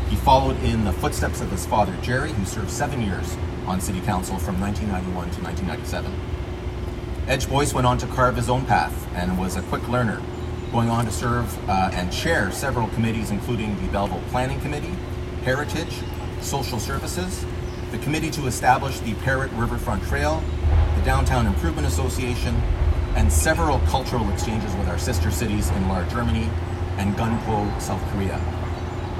Mayor Panciuk, who sat on council with Boyce from 2014 to 2018, spoke about Boyce’s accomplishments as a councillor.